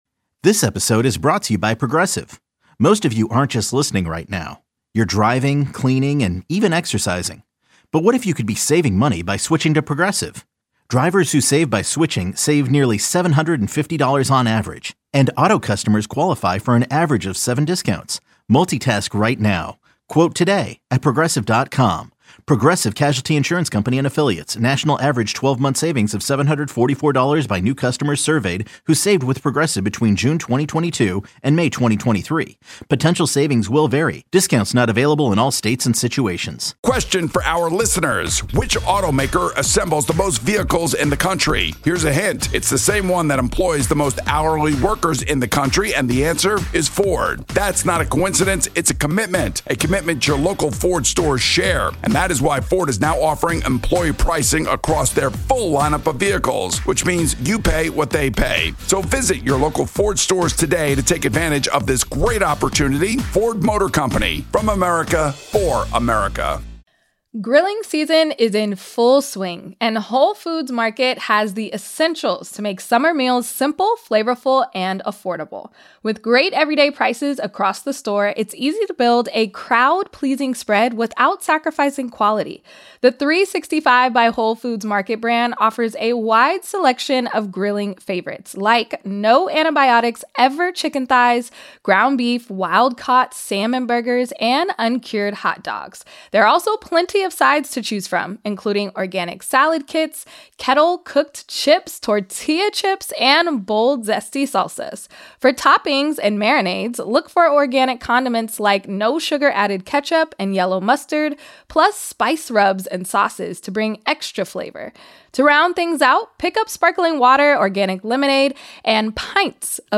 Boomer & Gio Audacy Sports, News 4.4 • 676 Ratings 🗓 22 May 2025 ⏱ 13 minutes 🔗 Recording | iTunes | RSS 🧾 Download transcript Summary A caller wants the players to get to go home right after a loss and speak to the media the next day instead. We played Marv Albert interviewing Boomer after his Super Bowl loss.